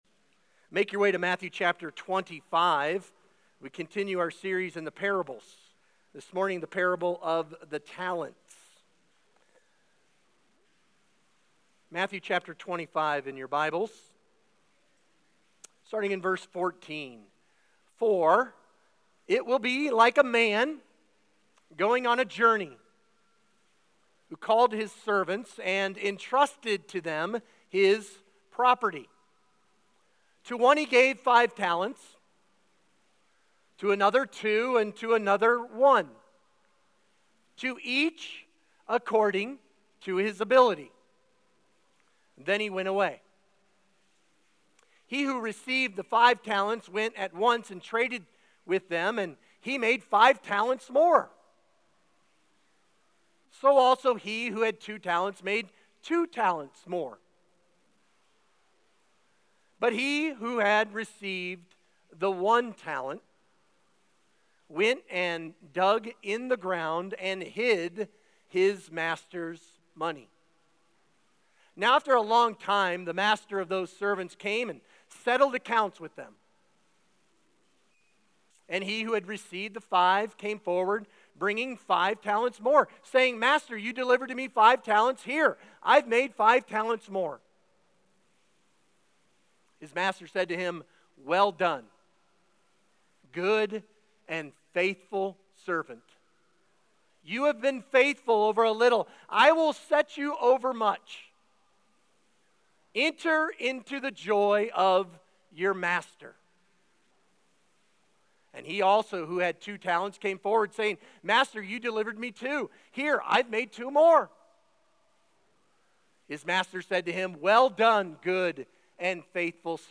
Sermon Discussion Read Matthew 25:14–30, Colossians 3:23-24, and Revelation 22:12 The Faithful Servants (Matthew 25:20–23) What stands out about the two faithful servants?